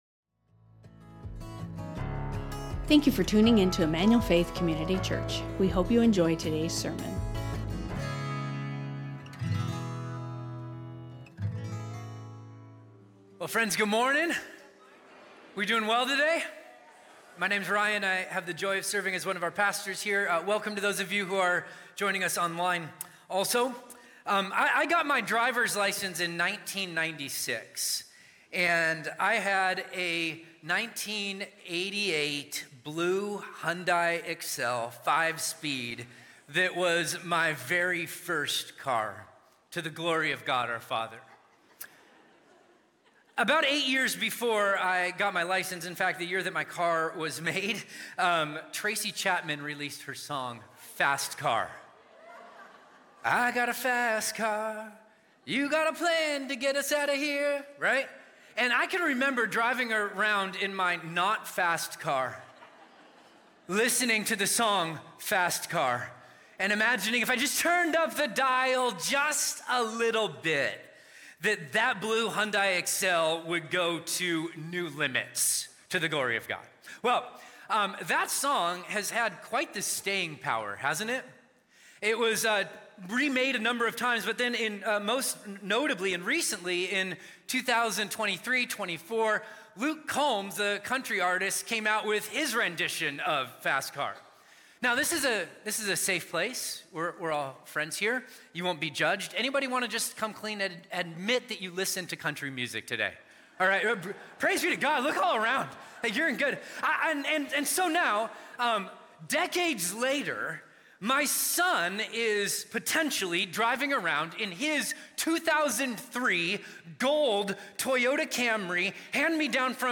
Emmanuel Faith Sermon Podcast Judgment and Mercy (Remix) | Revelation 8:1-9:21 Aug 18 2025 | 00:46:02 1x 00:00 / 00:46:02 Subscribe Share Spotify Amazon Music RSS Feed Share Link Embed